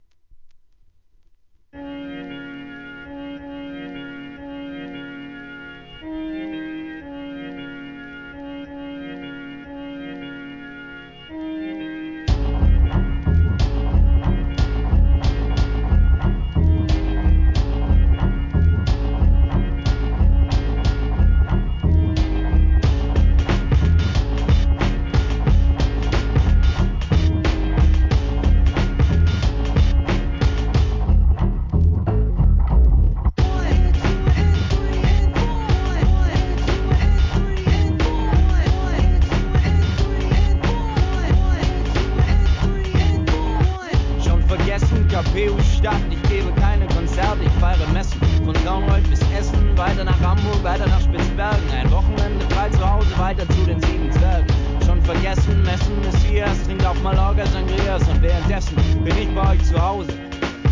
HIP HOP/R&B
の声ネタを使用したドイツ産人気アングラ!